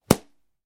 На этой странице представлены звуки подзатыльника – резкие, неожиданные аудиоэффекты.
Подзатыльник - Альтернативная версия 2